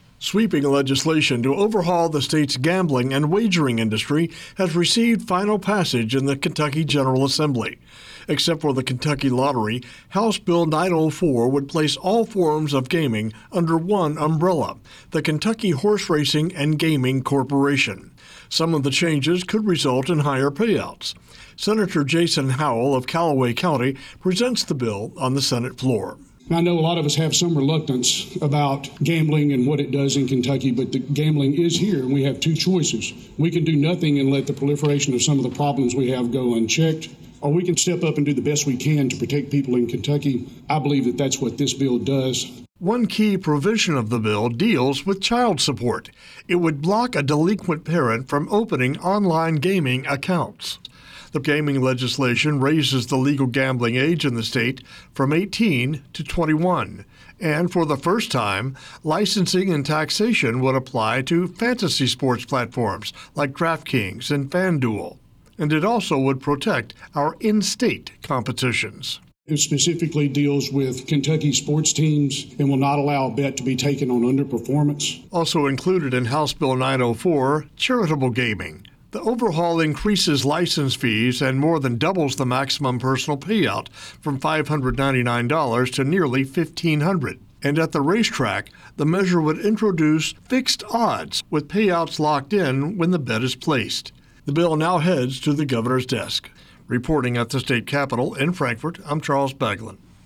LRC Public Information offers audio feeds over the Internet to help radio news directors obtain timely actualities and pre-produced news spots.